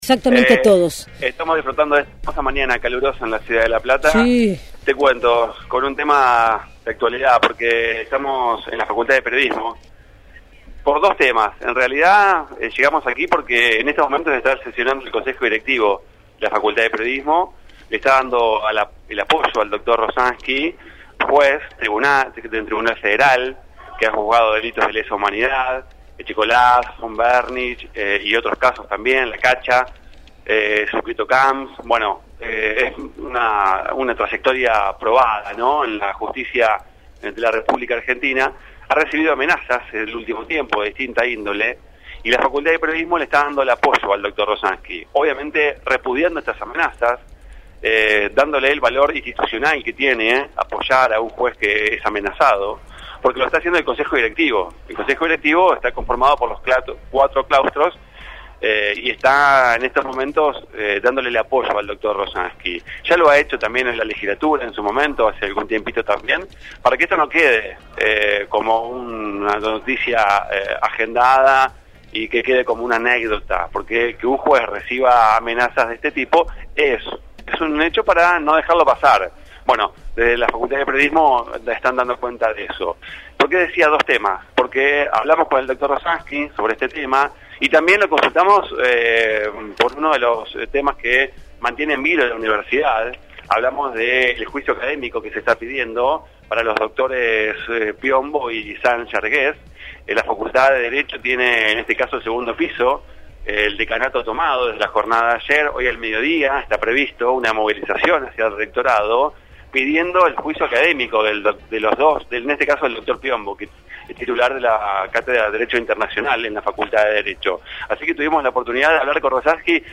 En diálogo con Radio Universidad Nacional de La Plata, el juez se refirió al apoyo recibido por parte de la unidad académica, así como también al fallo de Piombo y Sal Llargués.